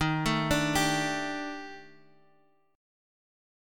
D#M7b5 chord